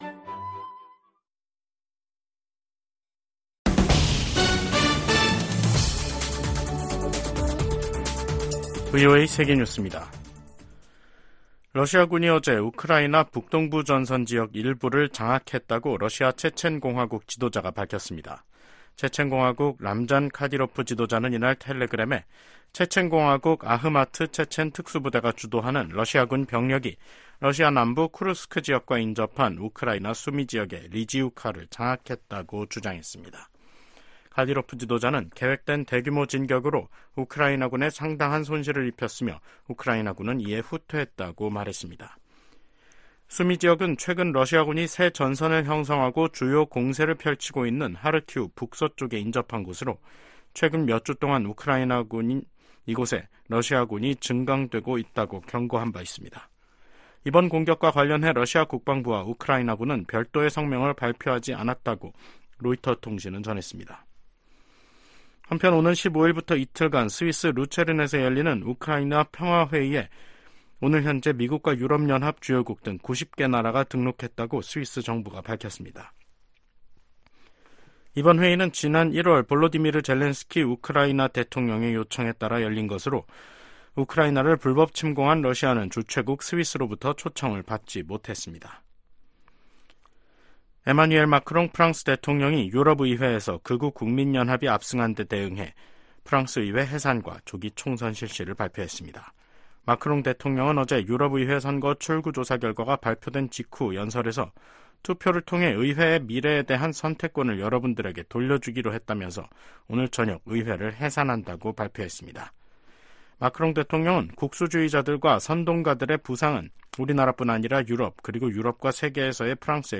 VOA 한국어 간판 뉴스 프로그램 '뉴스 투데이', 2024년 6월 10일 2부 방송입니다. 김여정 북한 노동당 부부장은 한국이 전단 살포와 확성기 방송을 병행하면 새로운 대응에 나서겠다고 위협했습니다. 한국 정부는 한국사회에 혼란을 야기하는 북한의 어떤 시도도 용납할 수 없다고 경고했습니다. 미국의 백악관 국가안보보좌관이 북한, 중국, 러시아 간 핵 협력 상황을 면밀이 주시하고 있다고 밝혔습니다.